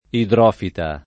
[ idr 0 fita ]